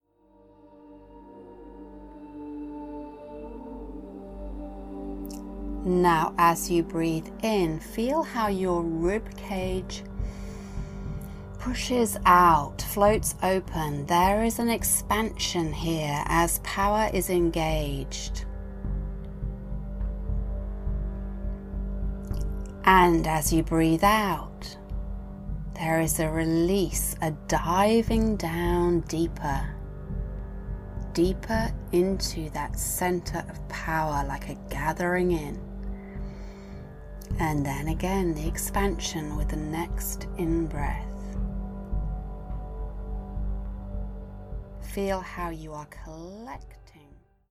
An empowering and powerfully healing meditation that reaches deep within us to the power and unequivocal strength inside us that is always there.